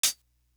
Closed Hats
Craze Hat.wav